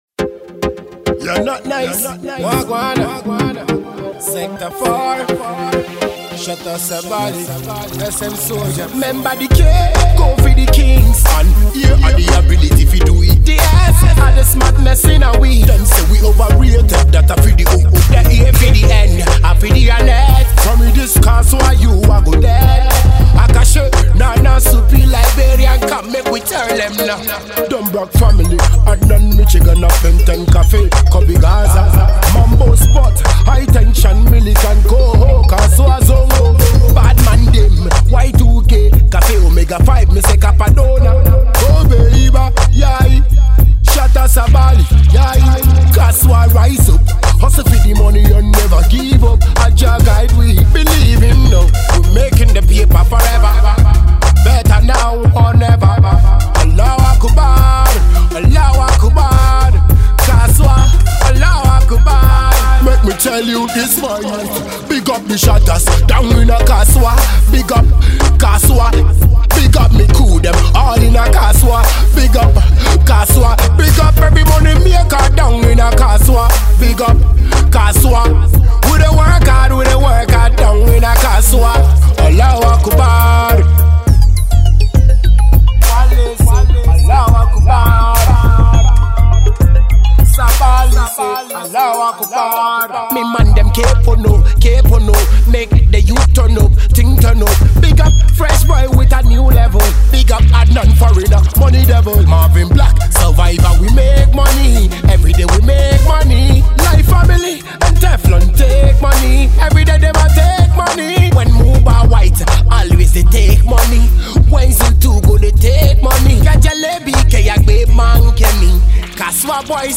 hot tune